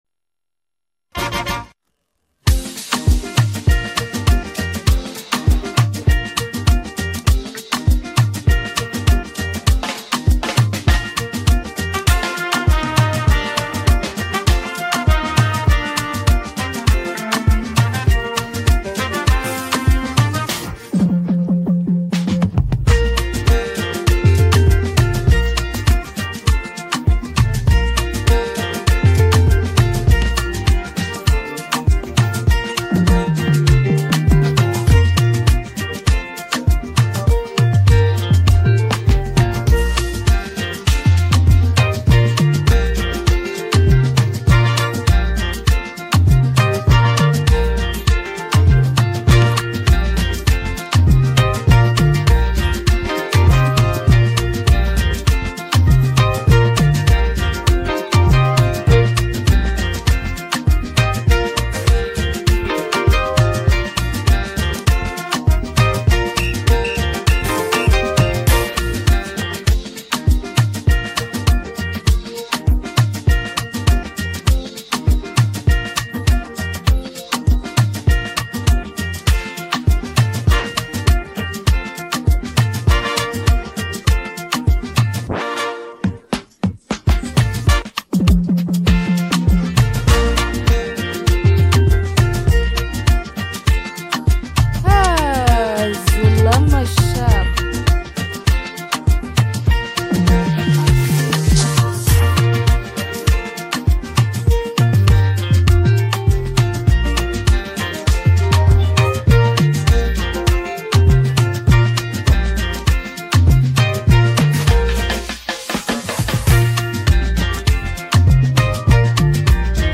Afrobeats Highlife